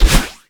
celeste_dash.wav